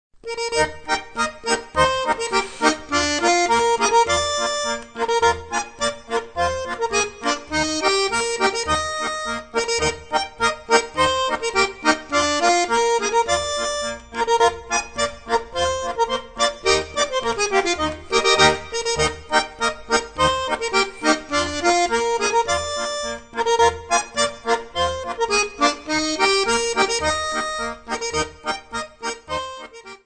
Besetzung: Schwyzerörgeli mit CD